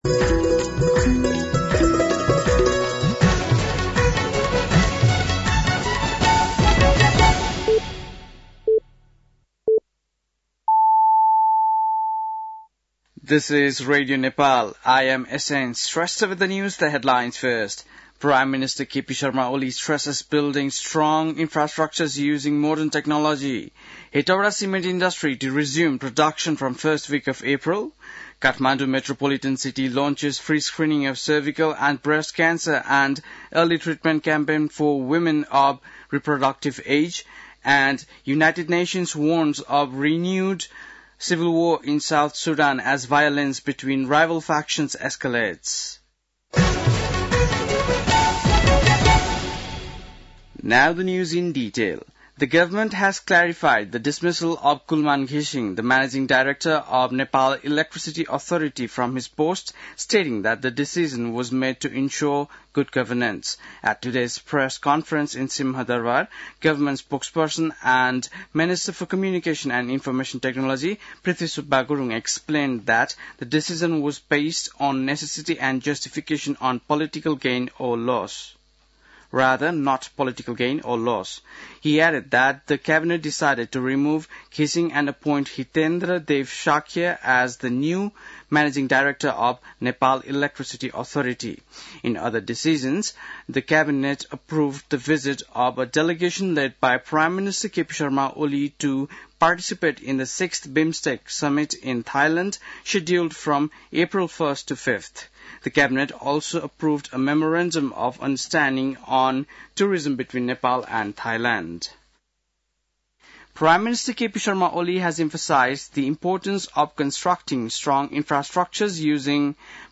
बेलुकी ८ बजेको अङ्ग्रेजी समाचार : १२ चैत , २०८१
8-PM-English-NEWS-12-12.mp3